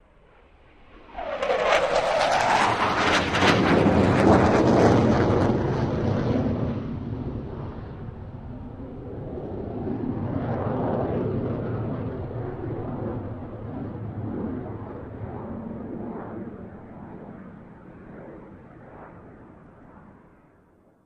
F-16 Fighting Falcon
F-16 By Medium